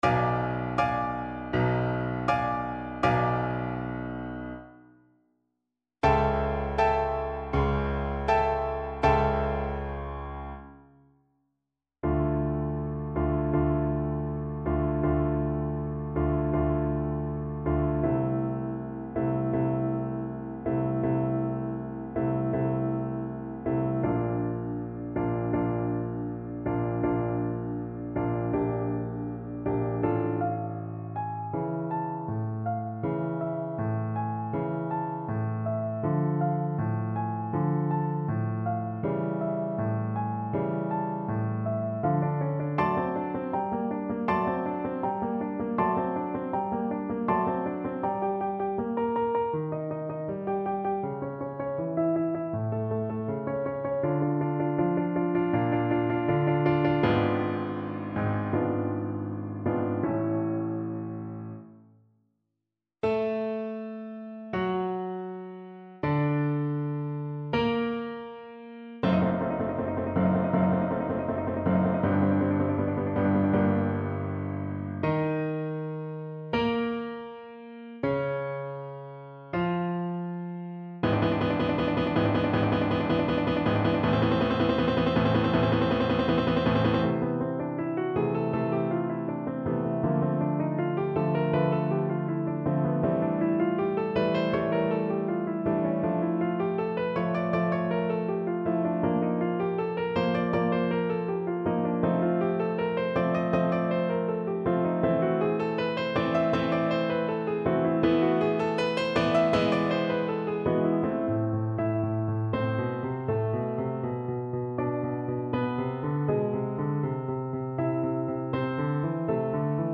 Classical Mozart, Wolfgang Amadeus Commendatore Scene from Don Giovanni Trumpet version
Play (or use space bar on your keyboard) Pause Music Playalong - Piano Accompaniment Playalong Band Accompaniment not yet available transpose reset tempo print settings full screen
4/4 (View more 4/4 Music)
D minor (Sounding Pitch) E minor (Trumpet in Bb) (View more D minor Music for Trumpet )
Andante
Classical (View more Classical Trumpet Music)